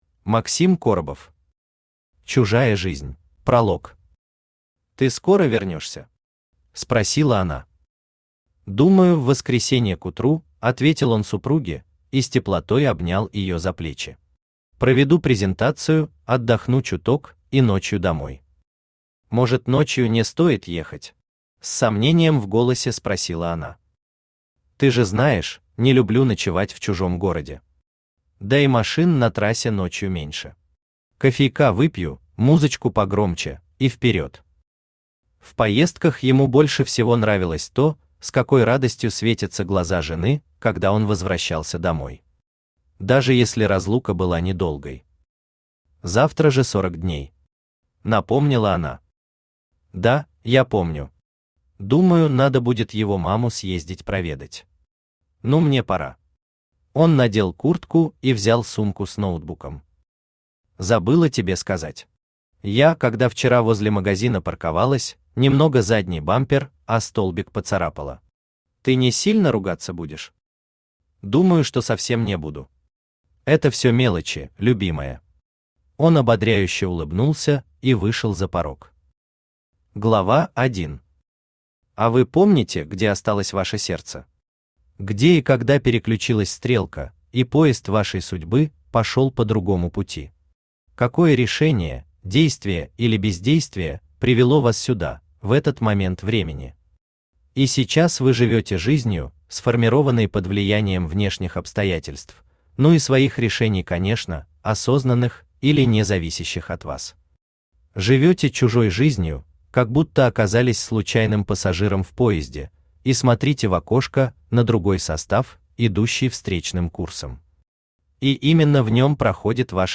Аудиокнига Чужая жизнь | Библиотека аудиокниг
Aудиокнига Чужая жизнь Автор Максим Коробов Читает аудиокнигу Авточтец ЛитРес.